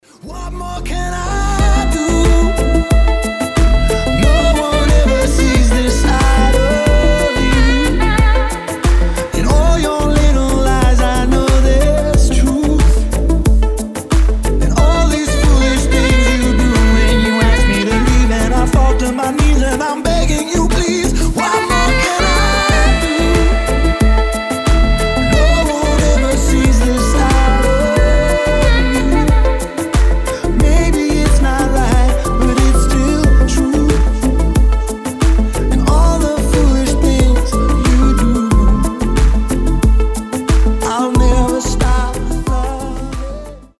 красивые
deep house
Cover
Саксофон